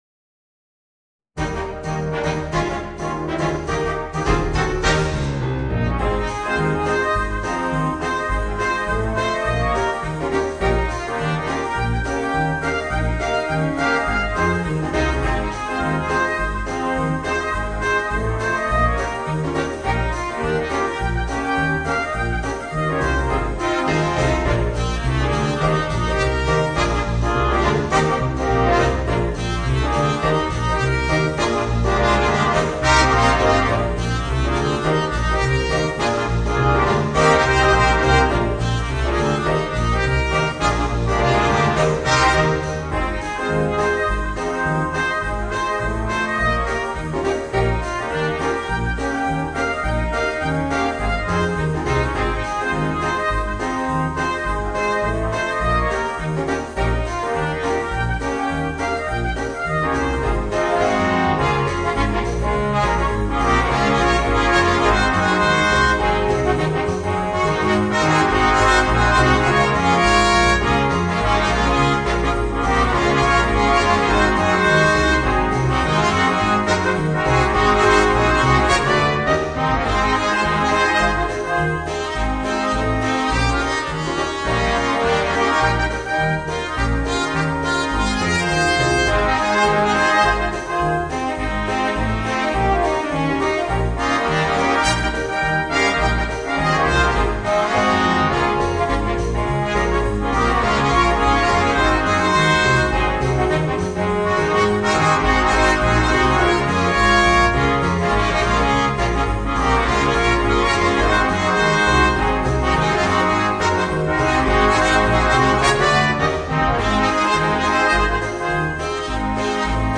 Voicing: 2 Trumpets, Horn, Trombone, Tuba and Piano